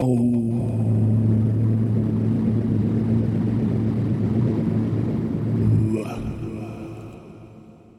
五小节，120 bpm。实际上是四小节，但我加了一个混响。